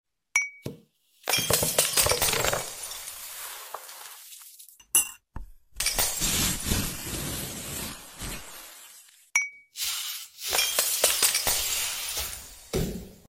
AI ASMAR Glas Video Sound Effects Free Download